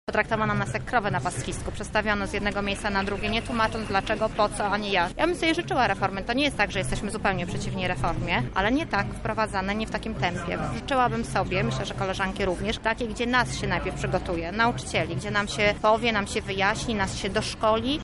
Pod Urzędem Wojewódzkim odbyła się dziś pikieta nauczycieli.
O sprzeciwie do reformy mówi jedna z pikietujących.